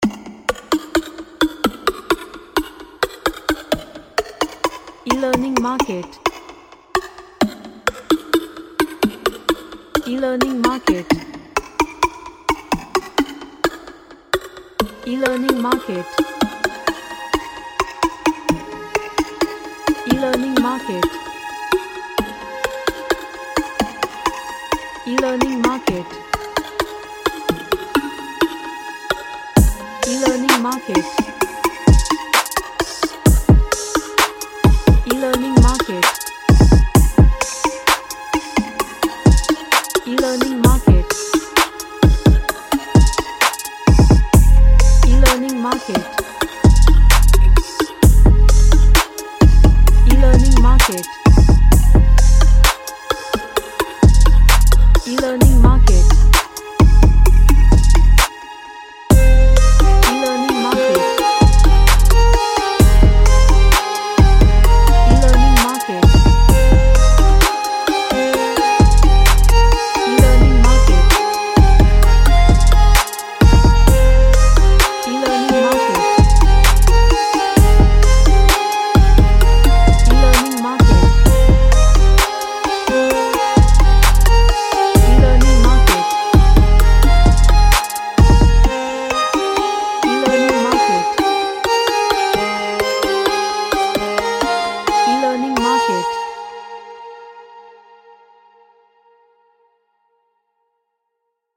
A dark sounding trap track
Dark / Somber